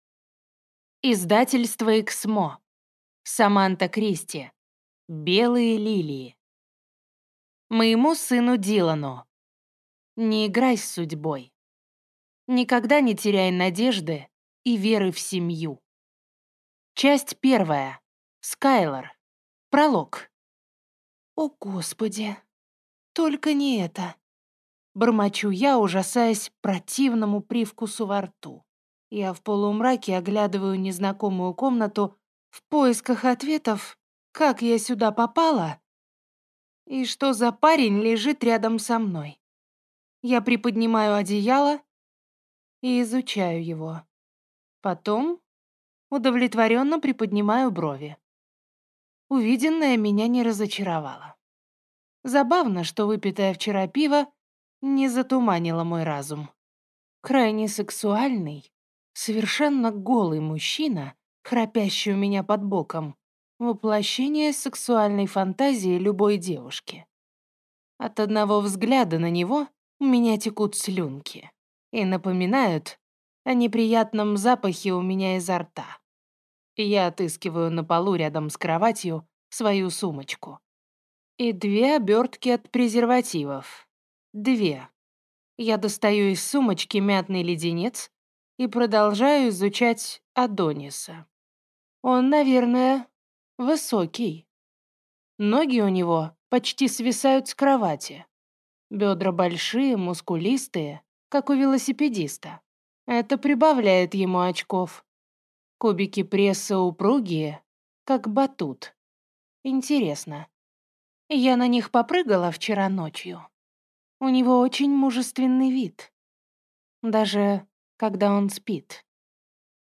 Аудиокнига Белые лилии | Библиотека аудиокниг